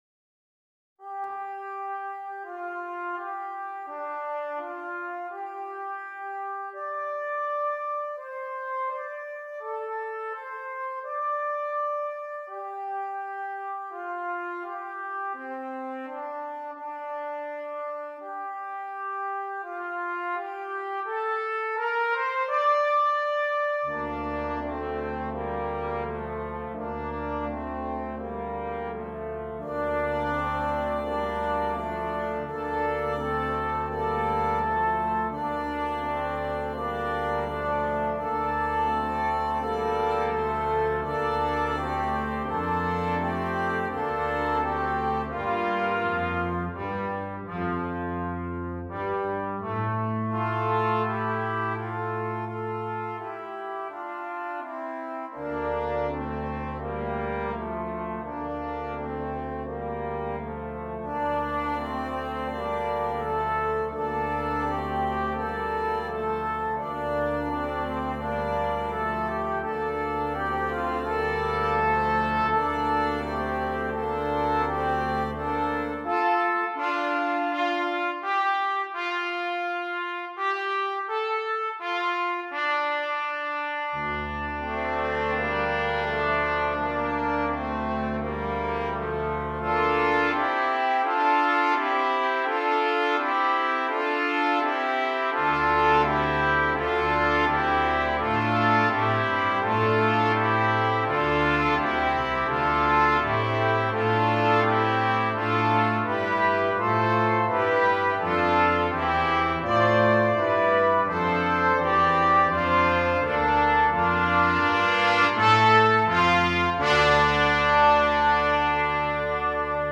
Brass Quintet (optional Percussion)
The haunting melody is found in all voices.